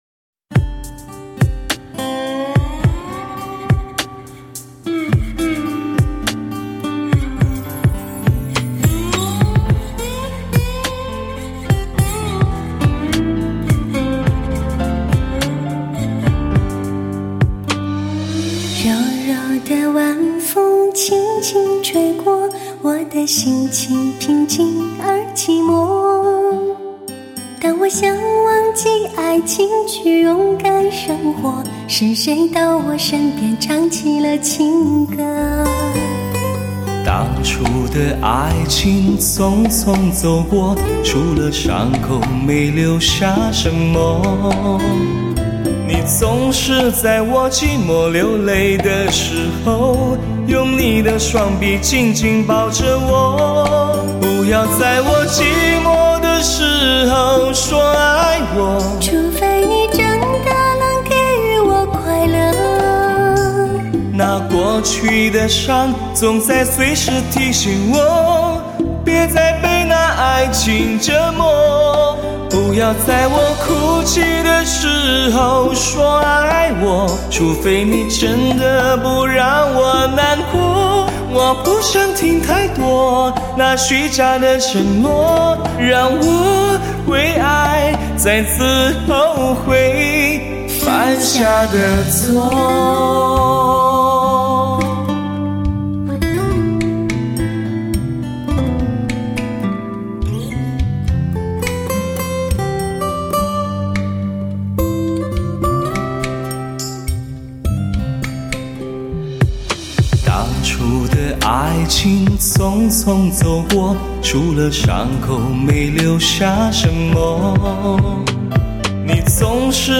浪漫情歌对唱